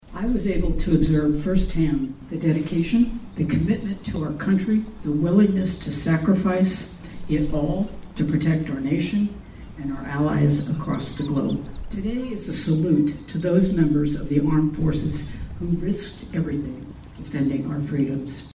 Governor Kelly speaks during ceremony